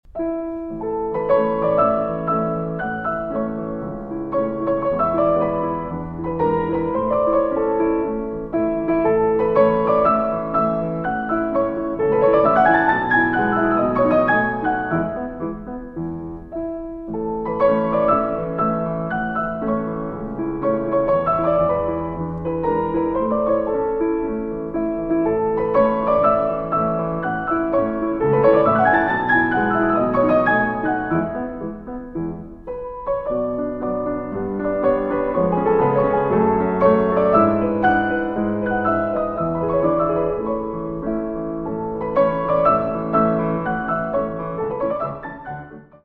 Allegro moderato (1:13)